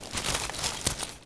wood_tree_branch_move_18.wav